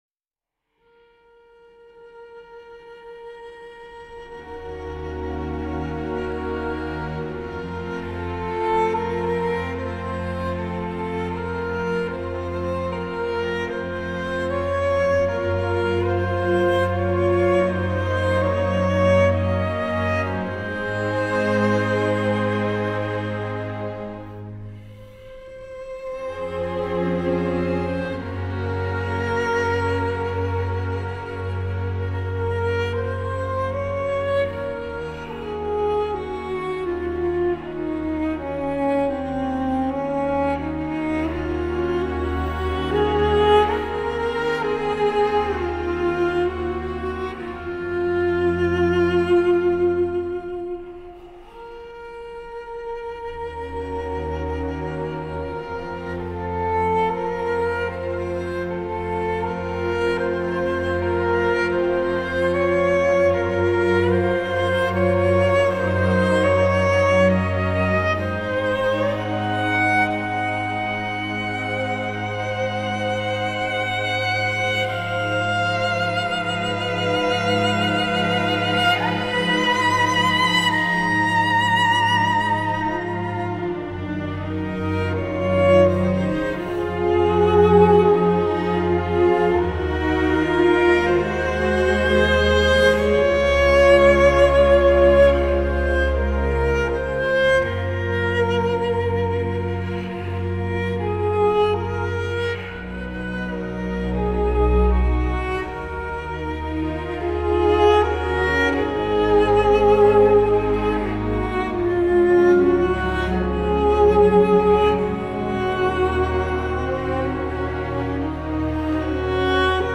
نوازنده ویولنسل